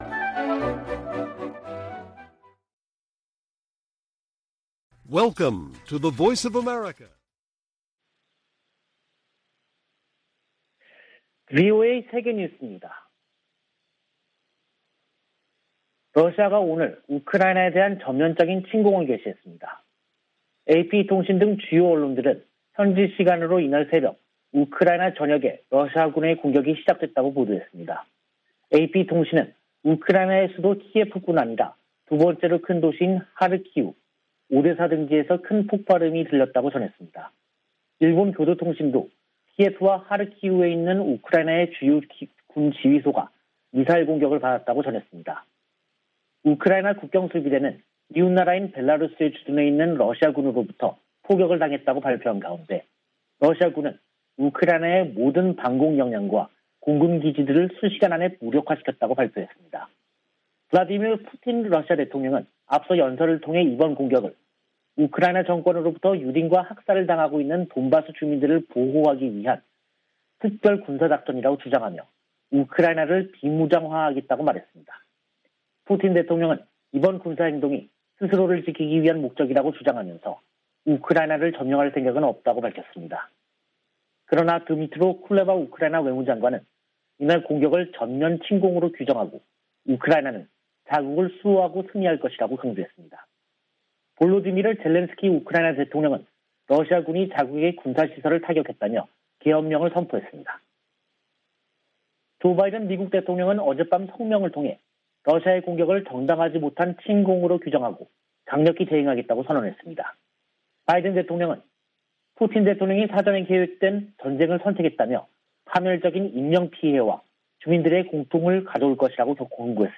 VOA 한국어 간판 뉴스 프로그램 '뉴스 투데이', 2022년 2월 24일 2부 방송입니다. 러시아의 우크라이나 침공으로 미-러 갈등이 격화되고 있는 가운데 북한의 외교 셈법이 복잡해졌다는 분석이 나오고 있습니다. 미 국방부는 우크라이나에 대한 한국의 지지 성명에 주목했다고 밝혔습니다. 미국의 전통적 대북 접근법으로는 북한 문제를 해결하는 데 한계가 있으며, 대통령의 리더십이 중요하다는 보고서가 나왔습니다.